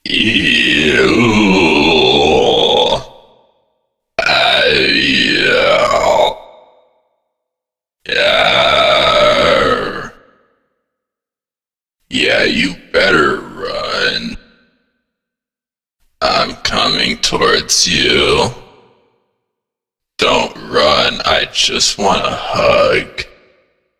Figure_Chasing_a_Player_Voicelines.ogg